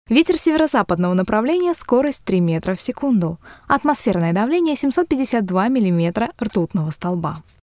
Слюни/щелчки при записи - как не допустить?
Дикторы на работе почти все читают, как в примере - щелчки какие-то, не знаю уж, от чего, слюни что ли во рту лопаются?